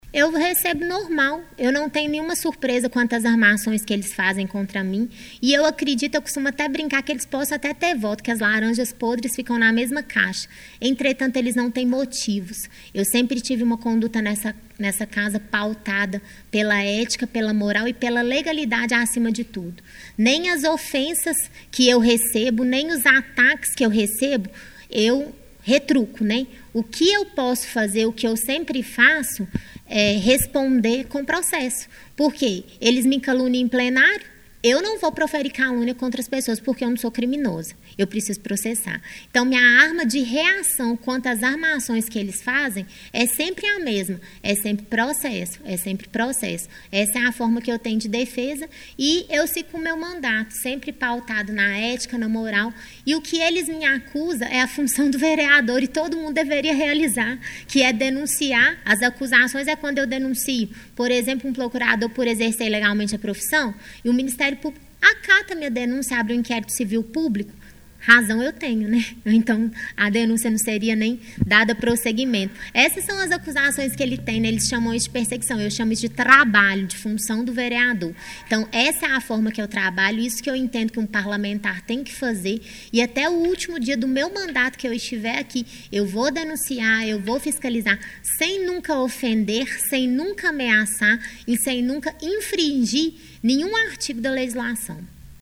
Ela convidou a imprensa para uma coletiva onde falou sobre o que vem sofrendo nos últimos meses.